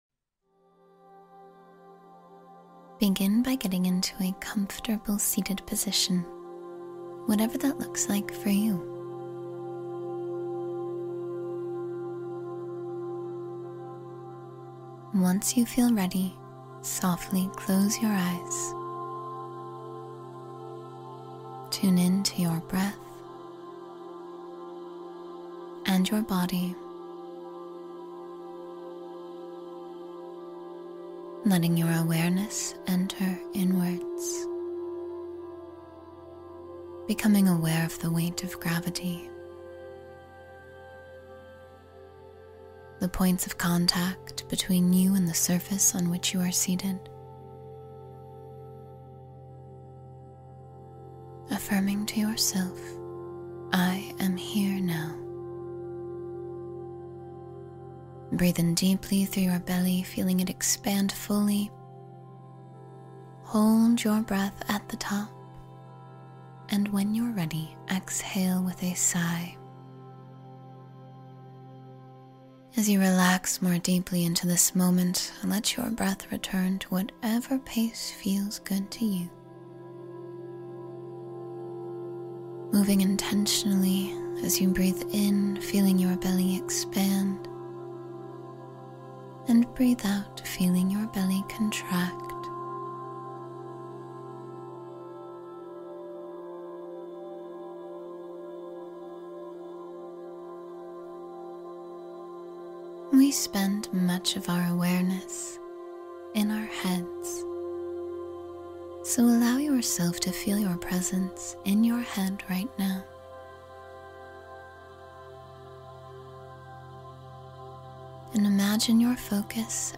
20 Minutes of Healing Calm and Presence — Guided Meditation for Deep Relaxation